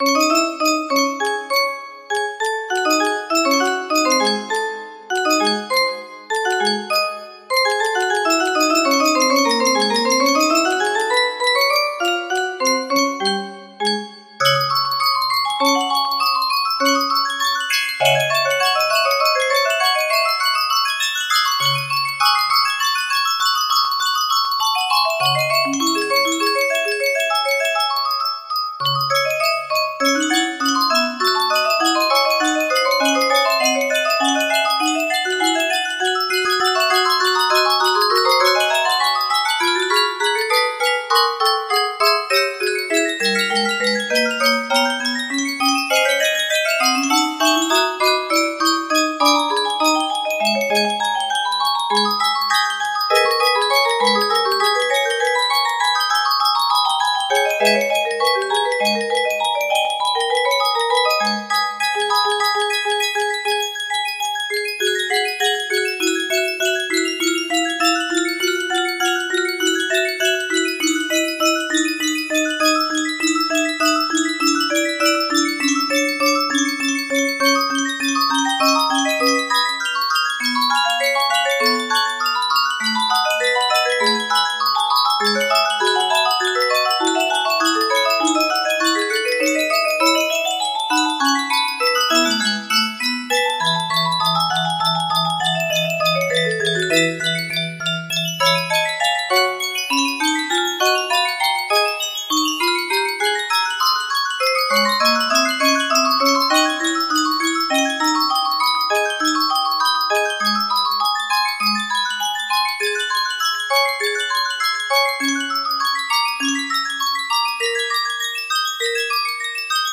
A Harp concerto in D minor music box melody
Full range 60